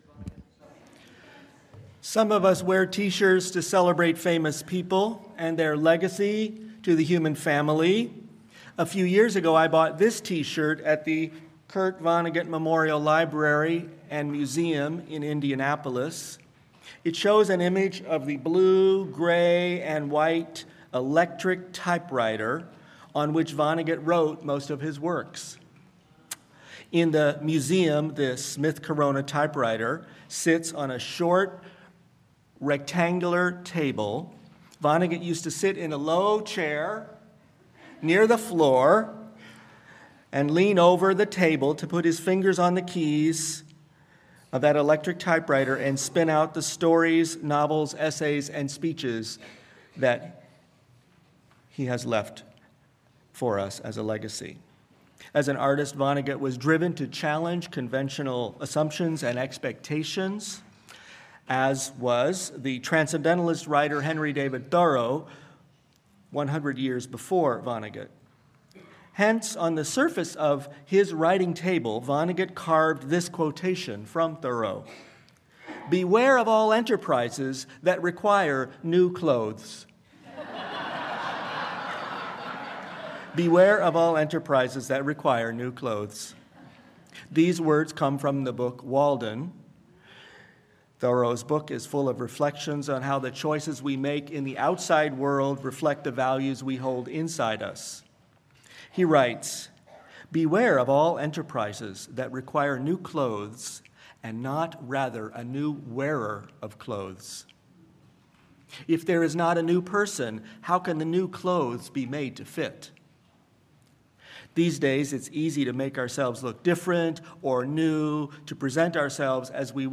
Homily-Tee-Shirt-Spirituality.mp3